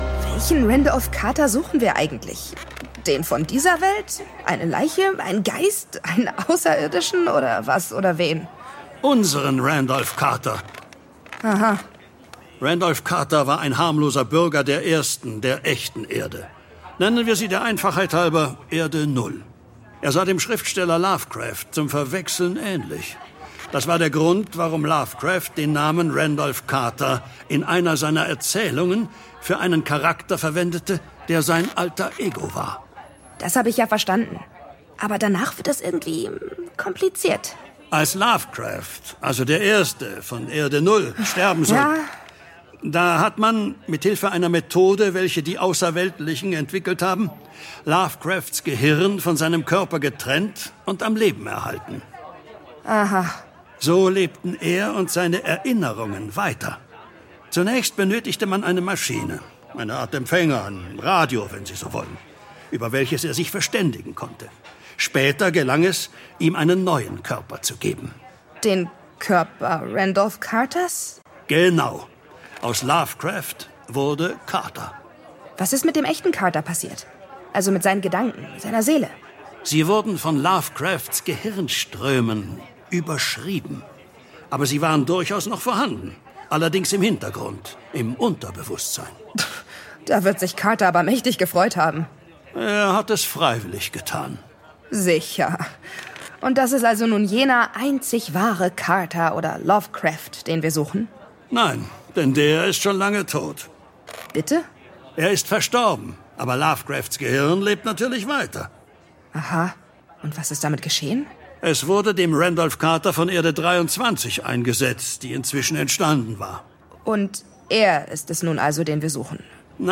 Heute gibt es das Finale der Hörspielserie Lovecraft - Chroniken des Grauens.